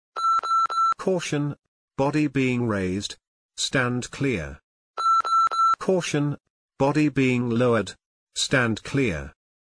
This compact alarm delivers a clear “Caution, body being raised/lowered, stand clear!” spoken alert at 95 dB @ 1 m, instantly warning pedestrians, operators and site crews of tipping movements to prevent accidents and meet safety requirements.
95dB @ 1m